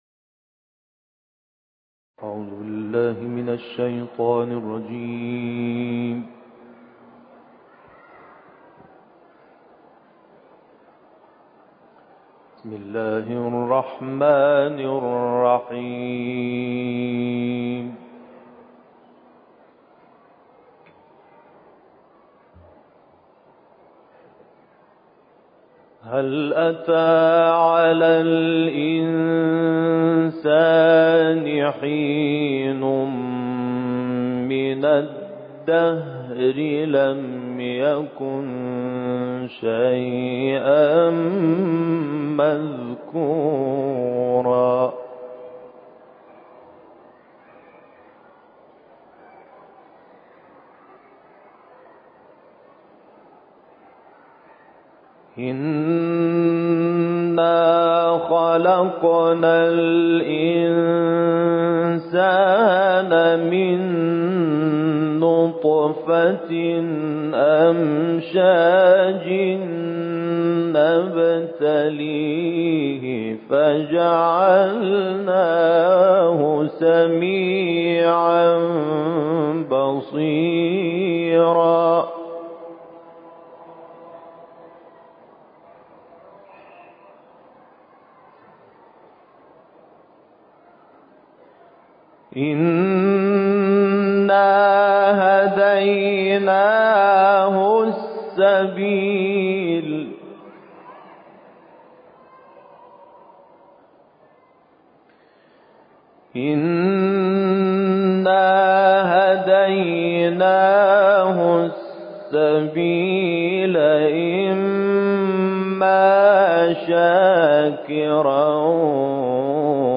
صوت/تلاوتی شاهکار از «محمود شحات انور»
در ادامه تلاوتی شاهکار از محمود شحات شامل آیات ۱ تا ۲۲ سوره انسان، سوره‌های شمس و حمد و آیات ۱ و ۲ سوره بقره تقدیم می‌شود.